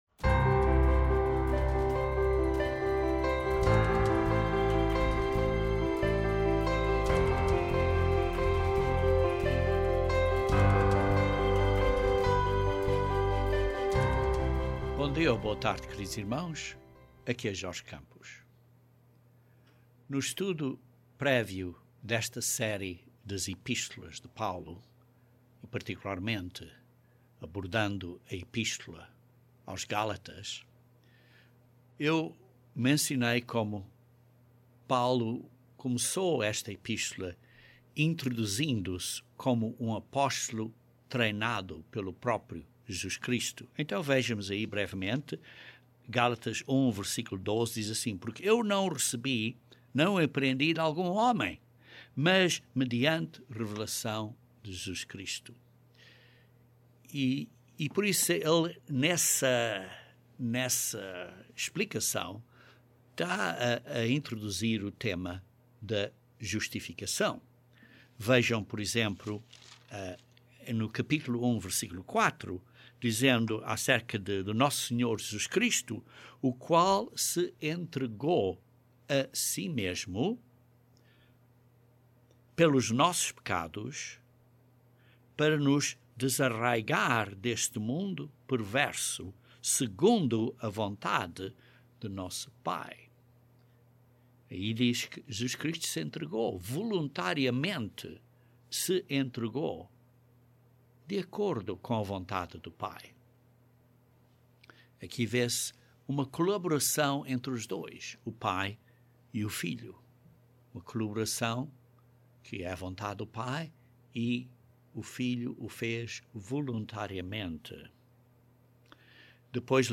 Este sermão é uma continuação do estudo bíblico da epístola aos Gálatas e descreve a posição de Paulo da justificação pela fé e não por obras de lei.